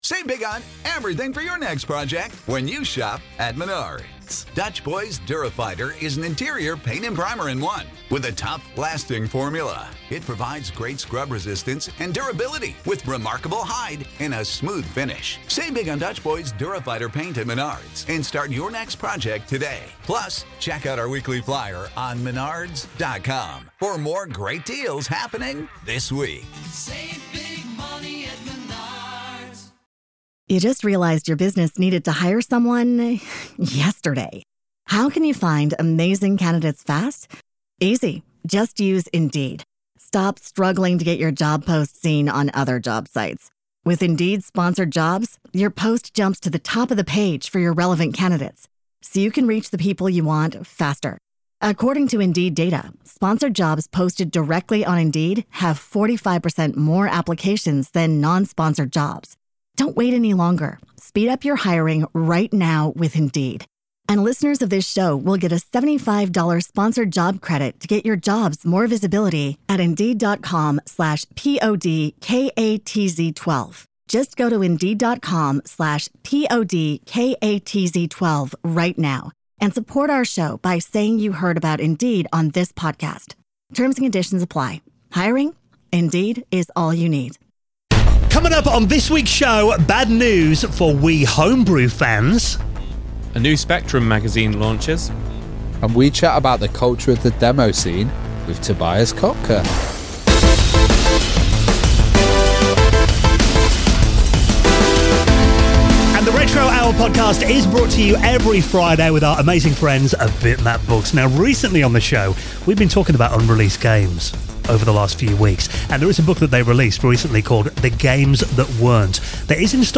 The Week's Retro News Stories